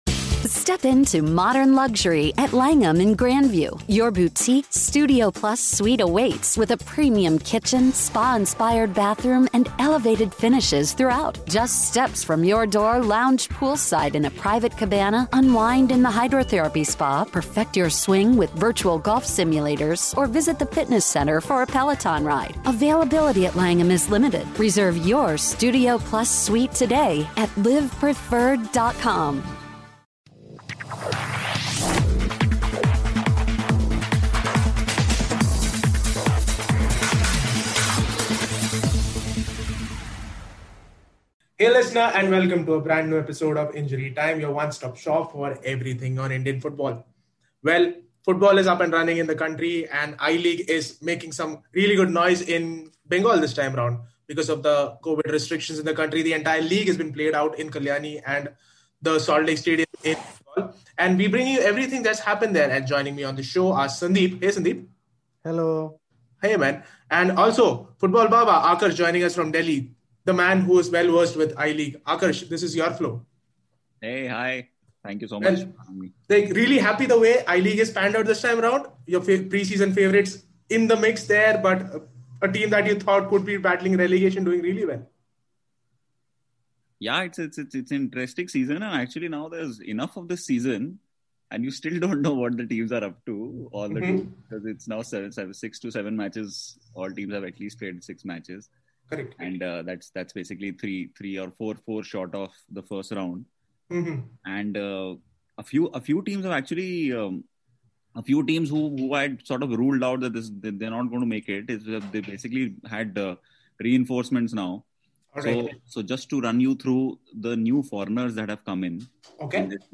We also look into TRAU's impressive season and much more It's a fun chat so check it out.